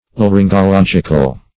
Laryngological \La*ryn`go*log"ic*al\, a.